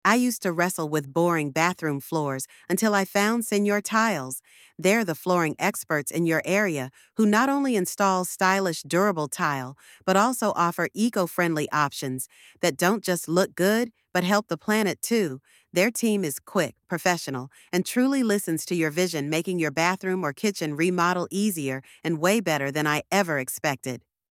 96185-voiceover.mp3